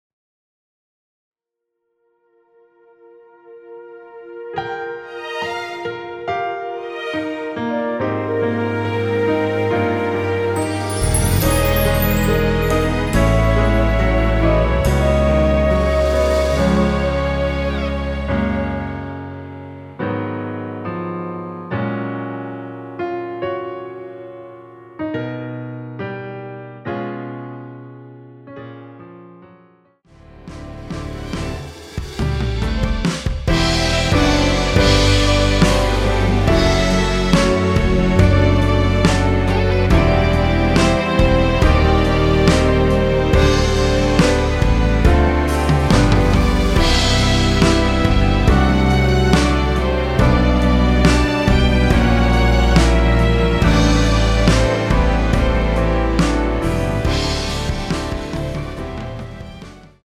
Gb
앞부분30초, 뒷부분30초씩 편집해서 올려 드리고 있습니다.
중간에 음이 끈어지고 다시 나오는 이유는